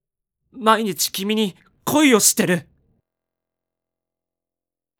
パワフル男性
ボイス